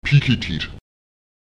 Lautsprecher piketet [Èpiketet] die Augen